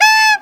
SAX JN SAX07.wav